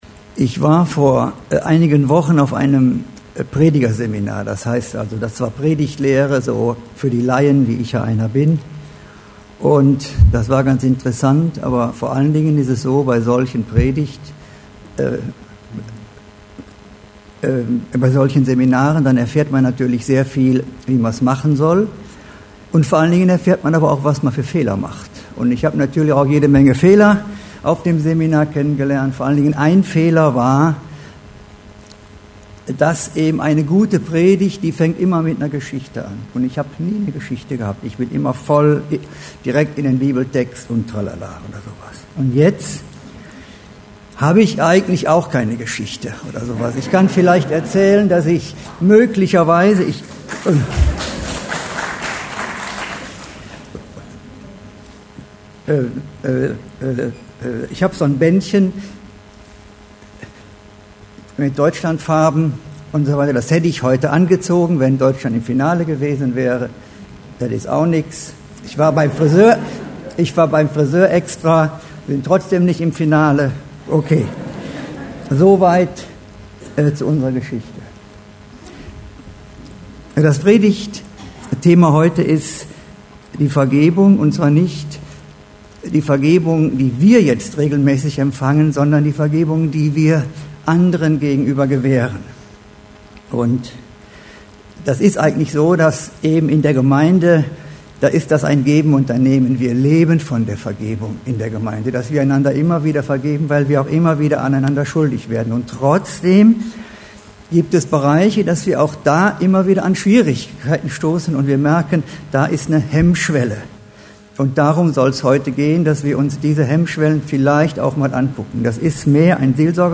Preacher
Einzelpredigten